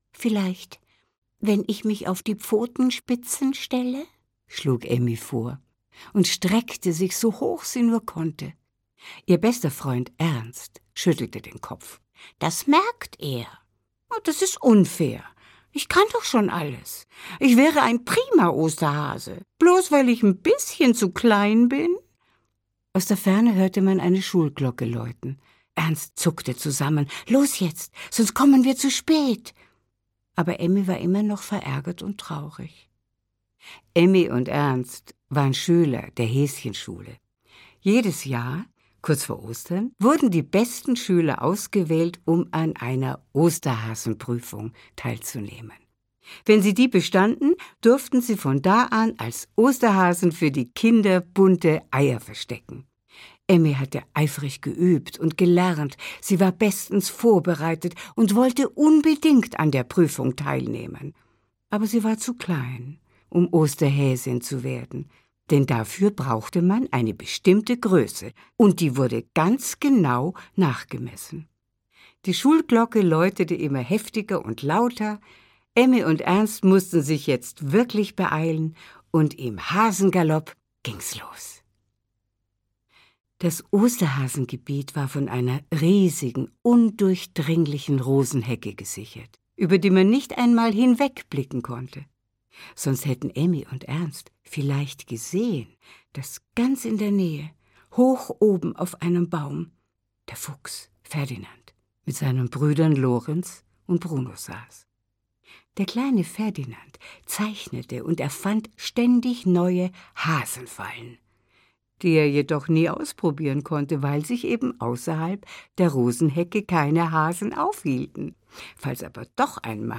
Die Häschenschule - Jagd nach dem goldenen Ei - Hortense Ullrich - Hörbuch